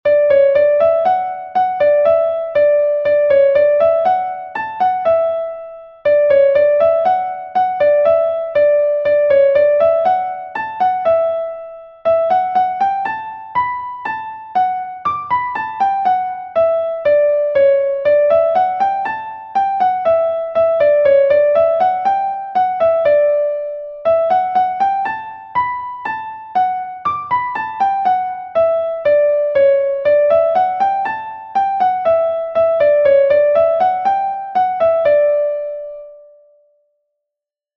Hanter dro